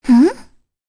voices / heroes / kr
Kirze-Vox_Think_kr_c.wav